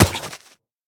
biter-roar-mid-4.ogg